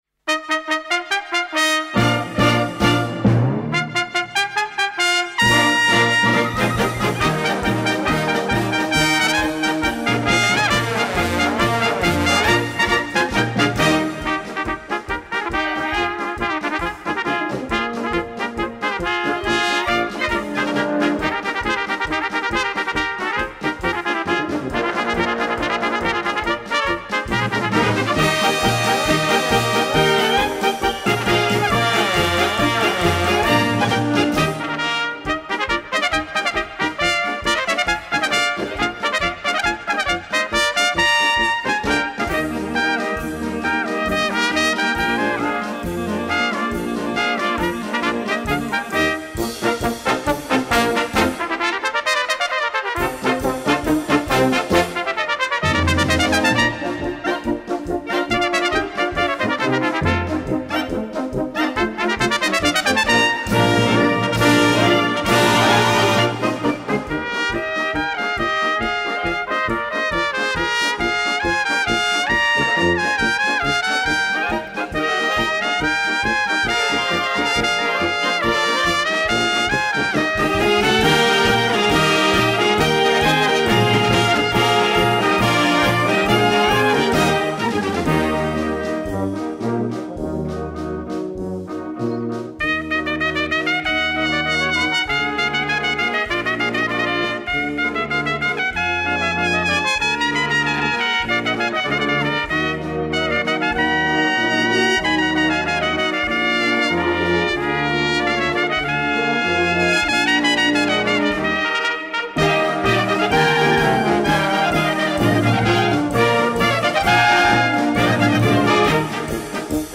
Solo für zwei Trompeten und Blasorchester
Besetzung: Blasorchester